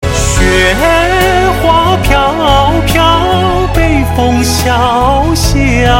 levelup.ogg